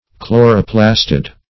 Search Result for " chloroplastid" : The Collaborative International Dictionary of English v.0.48: Chloroplastid \Chlo`ro*plas"tid\, n. [Gr. chlwro`s light green + E. plastid.]